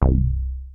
HOUSE MOOG 2 1.wav